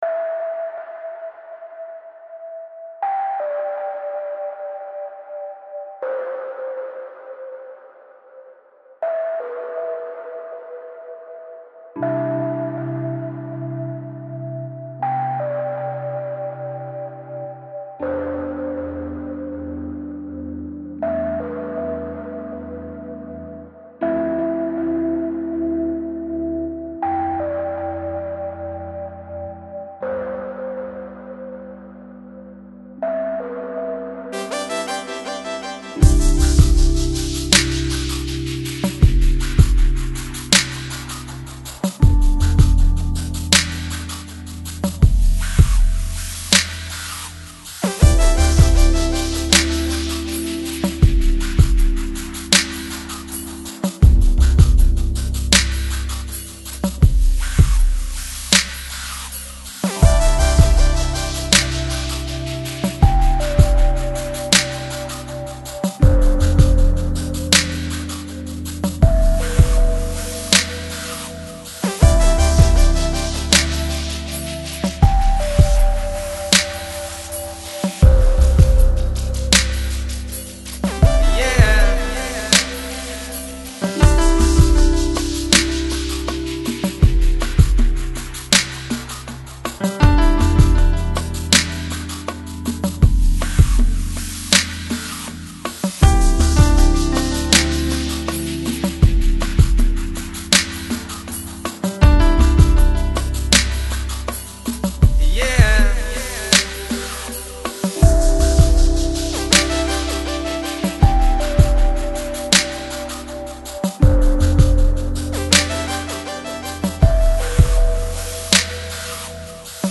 Жанр: Lo-Fi, Lounge, Chillout